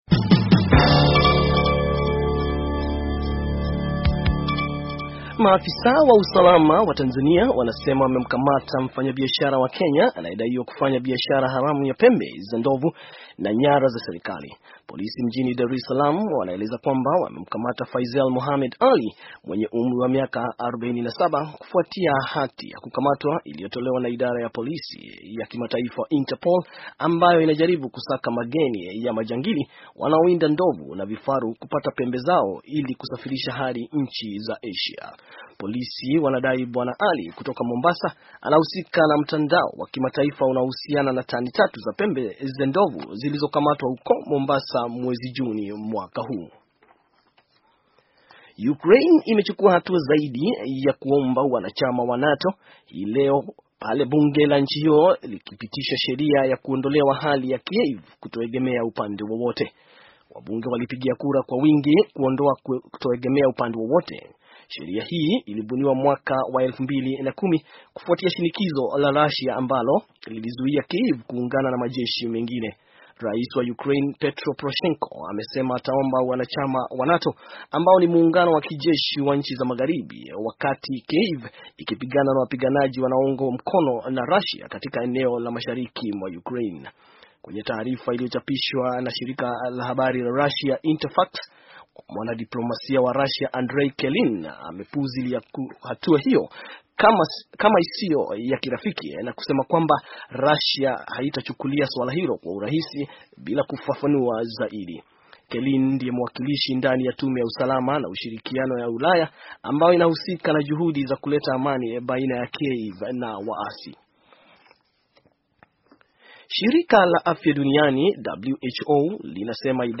Taarifa ya habari - 5:51